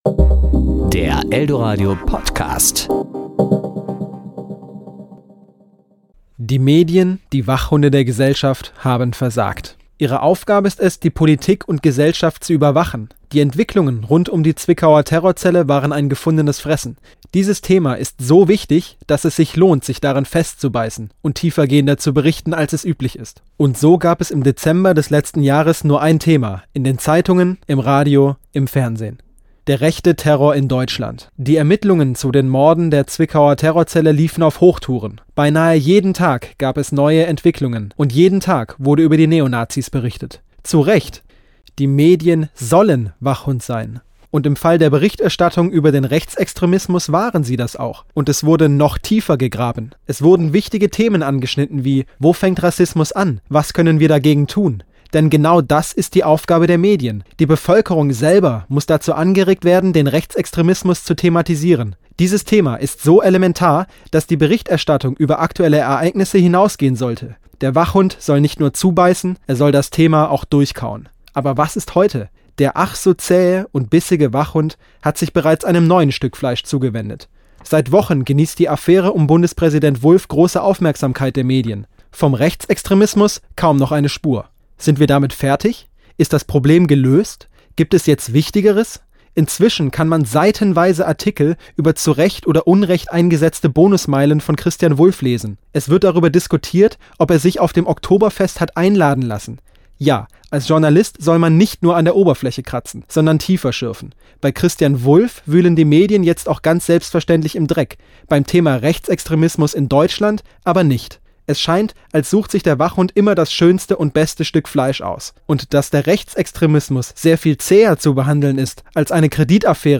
Wie aber ist die Berichterstattung zum Thema Rechtsextremismus zu bewerten? Ein Kommentar.